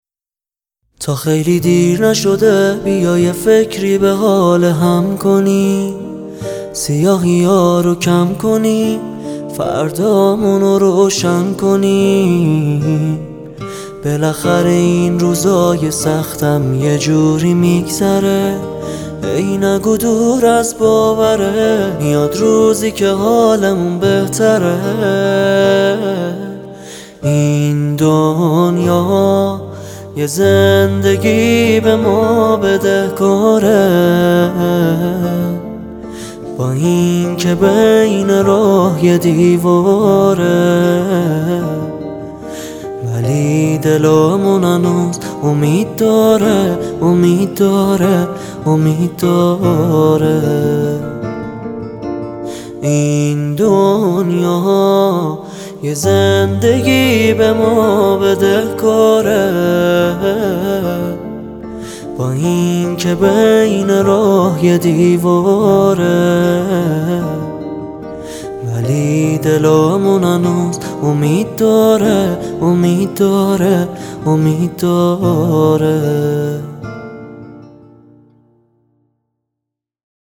آهنگ دلی
غمگین